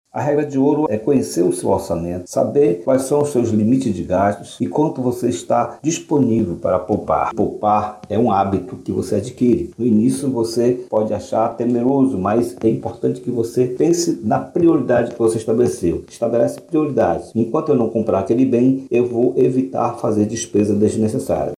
O economista e educador financeiro